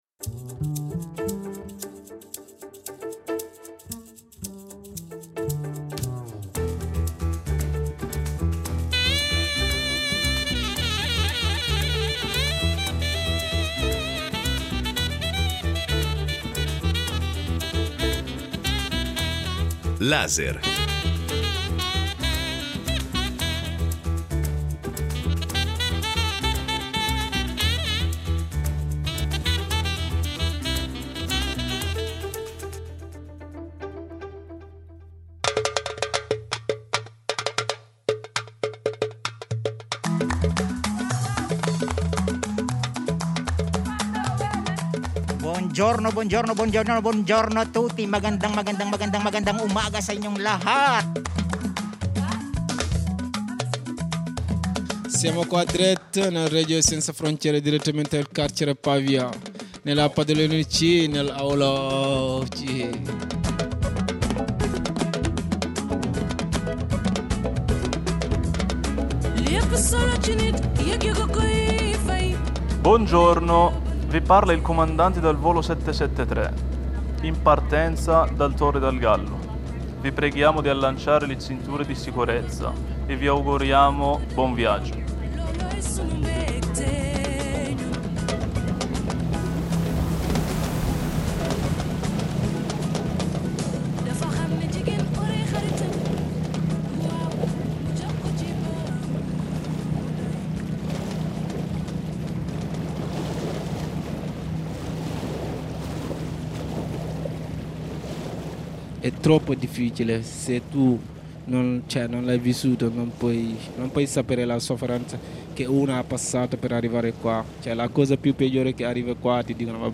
I detenuti, tutti migranti, recitano in più lingue il loro viaggio, scegliendo una parola, una canzone, un ricordo, da portare fuori dalle mura del carcere Torre del Gallo di Pavia. Dal Ghana alle Filippine, passando dall’Albania: suoni, accenti, lingue condivisi con un grande trasporto emotivo, e raccolti durante due mesi di laboratorio. Un'esperienza radiofonica di Shareradio all’interno del carcere di Pavia.